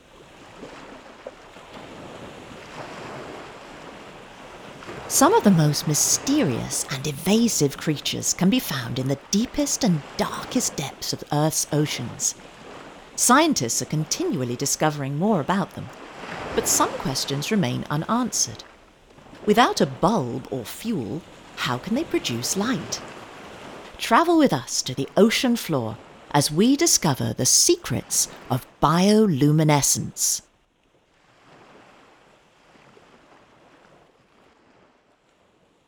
British English Speaker with a wide range of regional and international accents. Young to middle-age.
Sprechprobe: Industrie (Muttersprache):